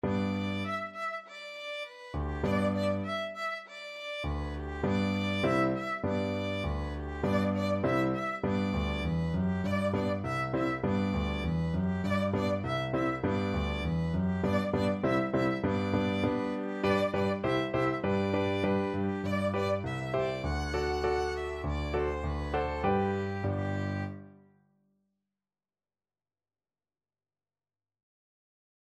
Violin
4/4 (View more 4/4 Music)
Allegro moderato (View more music marked Allegro)
G major (Sounding Pitch) (View more G major Music for Violin )
World (View more World Violin Music)